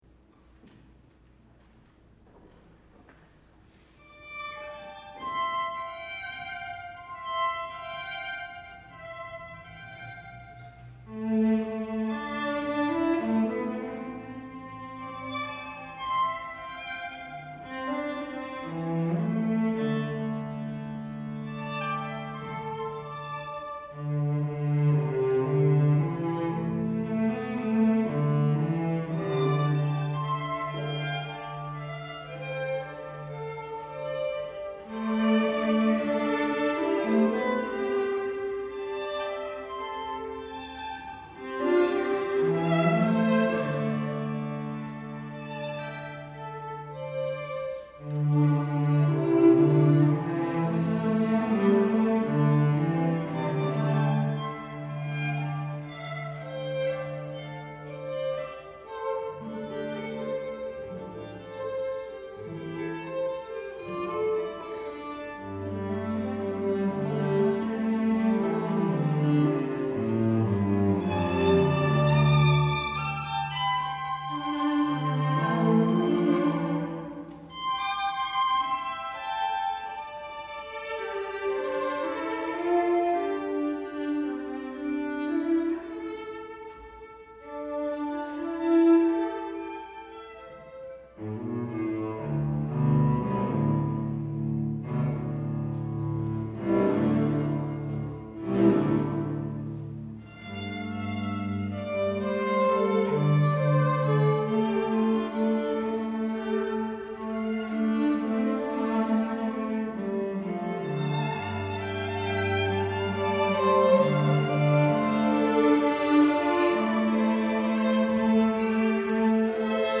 for String Quartet (2014)